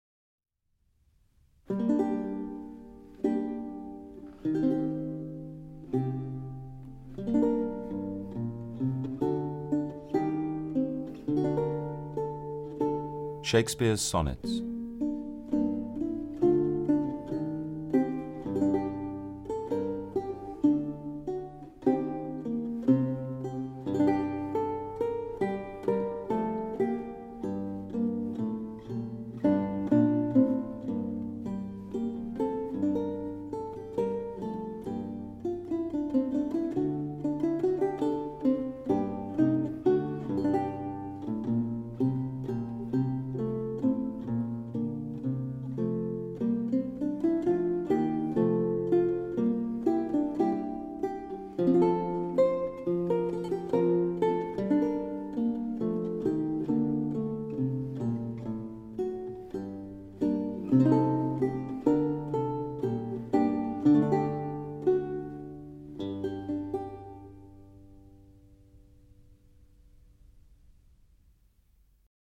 The Sonnets (EN) audiokniha
Ukázka z knihy
• InterpretAlex Jennings